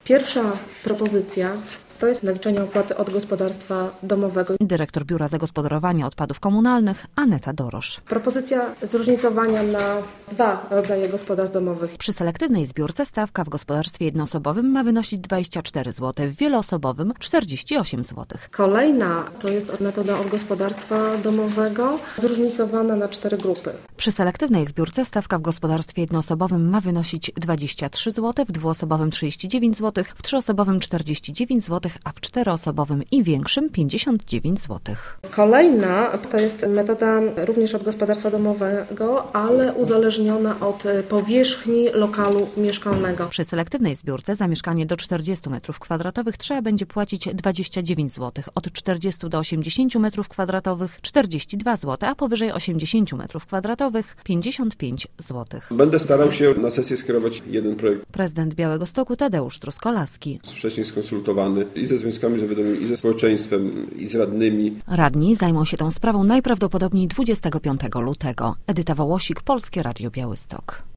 Konsultacje społeczne w sprawie opłat za wywóz śmieci - relacja